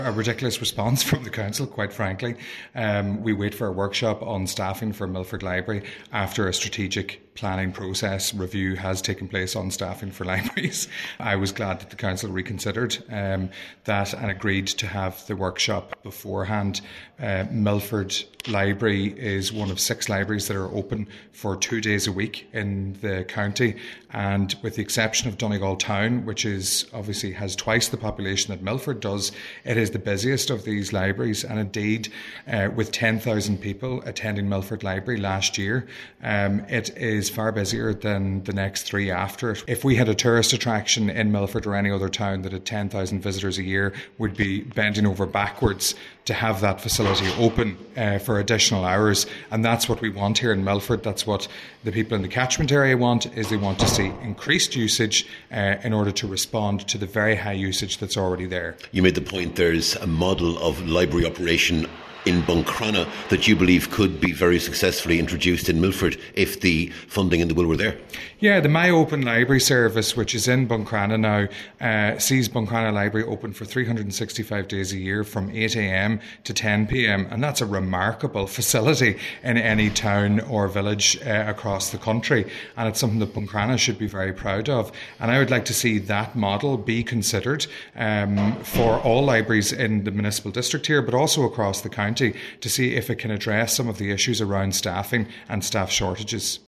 However, Cllr Declan Meehan who moved the original motion says it makes more sense for members to discuss the issue while staffing levels are being reviewed………